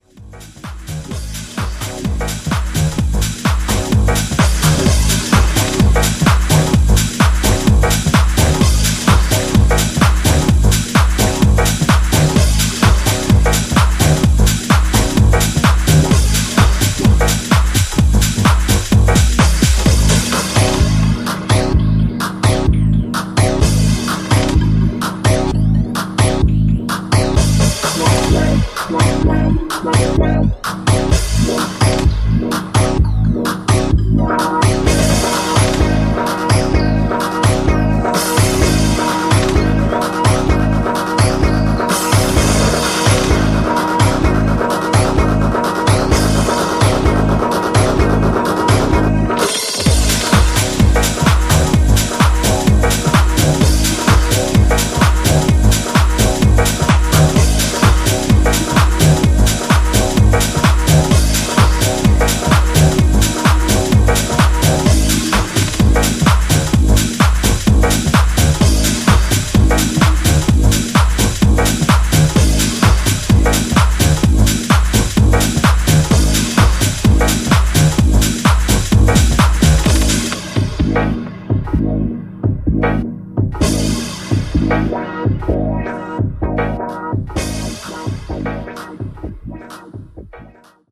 ジャンル(スタイル) TECH HOUSE / DEEP HOUSE / MINIMAL